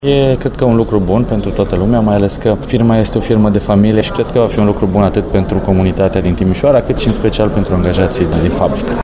La rândul său, prefectul de Timis, Eugen Dogariu, spune ca este important ca fabrica să îşi desfasoare activitatea la Timisoara